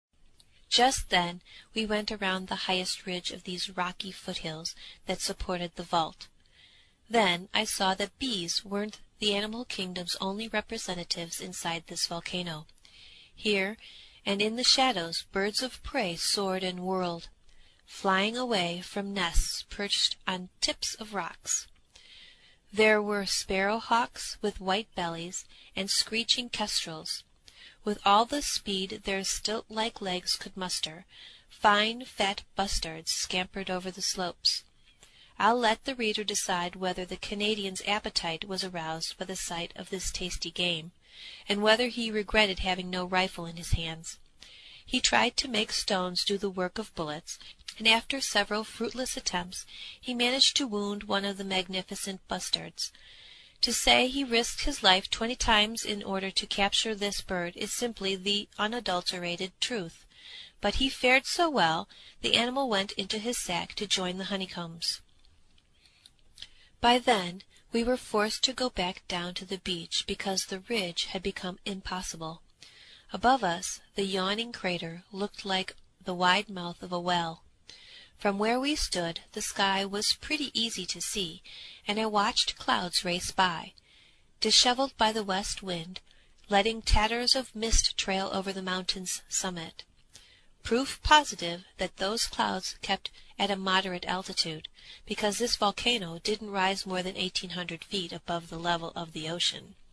英语听书《海底两万里》第405期 第25章 地中海四十八小时(31) 听力文件下载—在线英语听力室